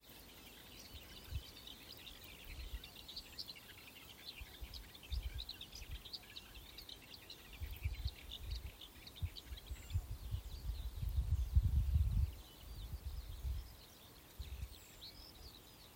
Klusais ķauķis, Iduna caligata
Administratīvā teritorijaAlūksnes novads
СтатусПоёт